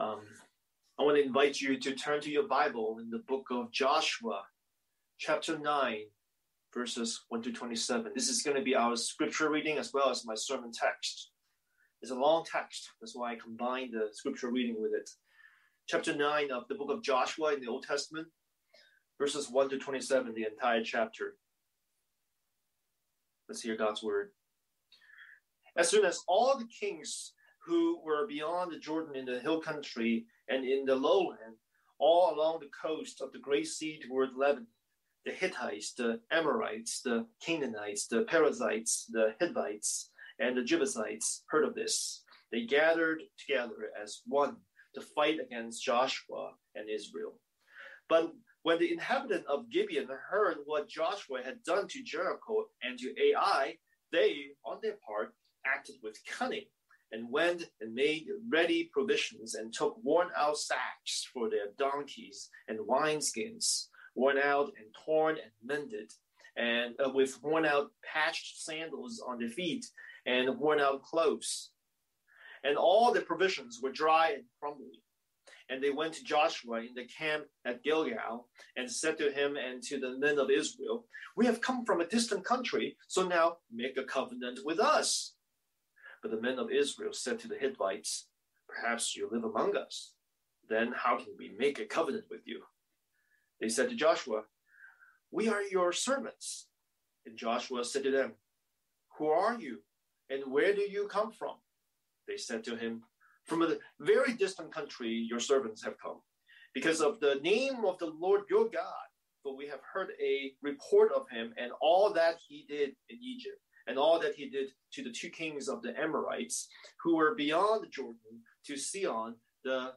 Scripture: Joshua 9:1-27 Series: Sunday Sermon